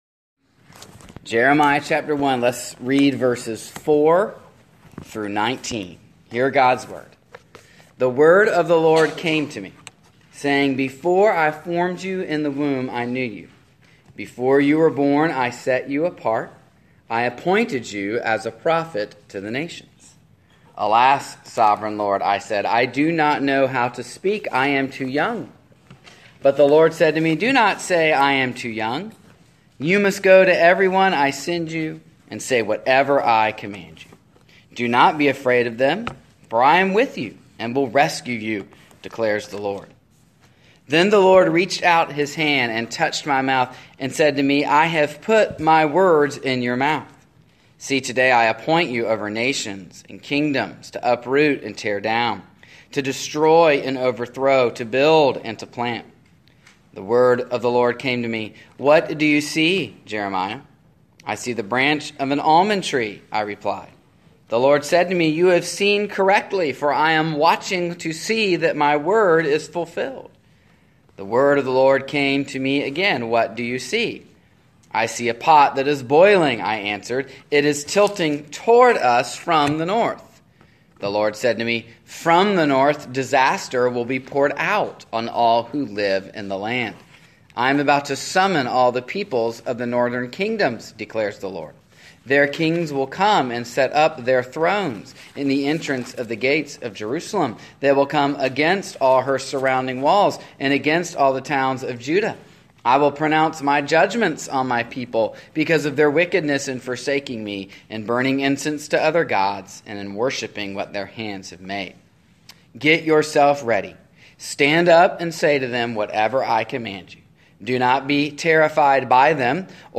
Jeremiah 1:4-19 Service Type: Sunday Evening The Old Testament survey continues with the Book of Jeremiah.